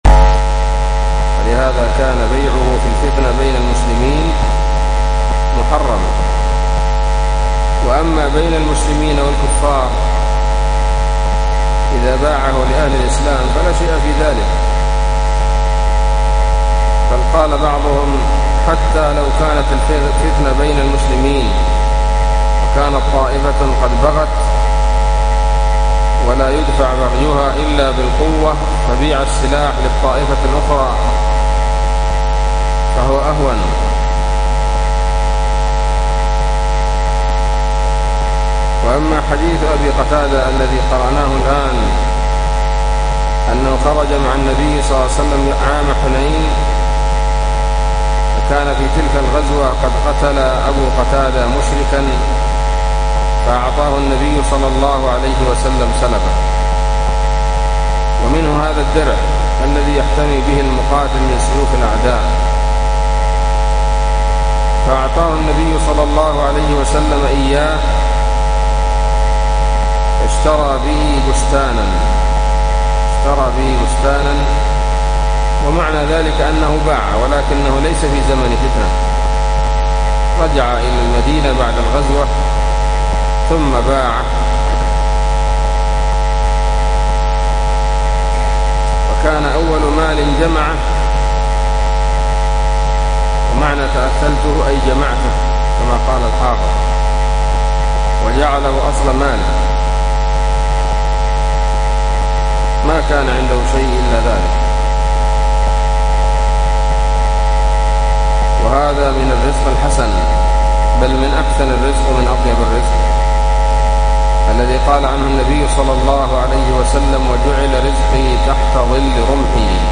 بسم الله الرحمن الرحيم الدرس الثاني والثلاثون : بَاب: بَيْعِ السِّلَاحِ فِي الْفِتْنَةِ وَغَيْرِهَا(ملاحظة : سقط من التسجيل قراءة المتن لهذا الدرس)، و بَاب: فِي الْعَطَّارِ، وَبَيْعِ الْمِسْكِ